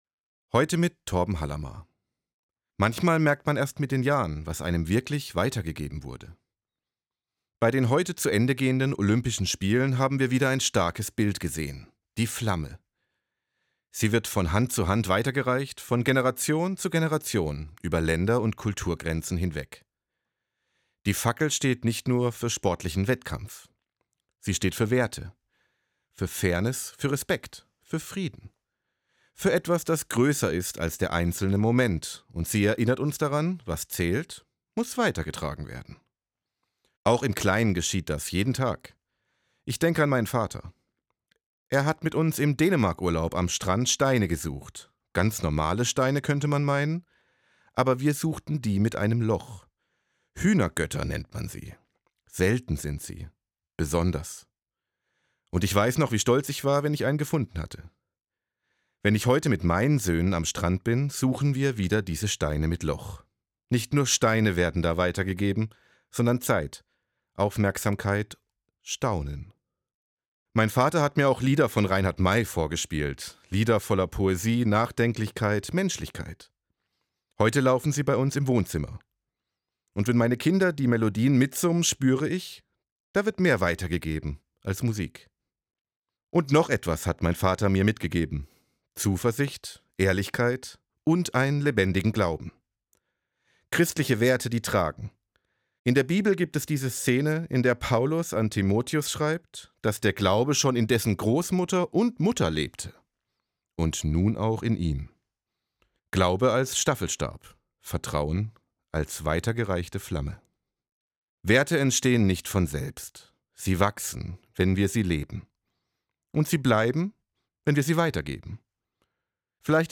An jedem vierten Sonntag im Monat verantwortet die Gebietskirche Süddeutschland eine Sendung im Hörfunkprogramm des Südwestrundfunks: Sie wird im Magazin aus Religion, Kirche und Gesellschaft „SWR1 Sonntagmorgen“ ausgestrahlt, jeweils um 7:27 Uhr (Verkündigungssendung mit 2,5 Minuten Sendezeit).